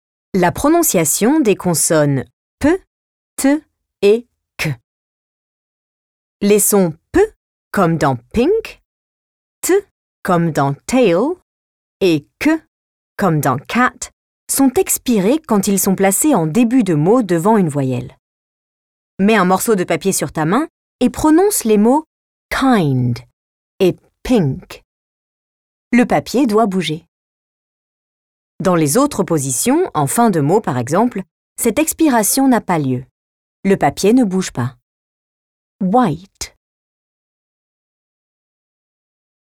U6 • Pronunciation • La prononciation des consonnes /p/, /t/ et /k/